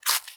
File:Sfx creature penguin foot slow walk 03.ogg - Subnautica Wiki
Sfx_creature_penguin_foot_slow_walk_03.ogg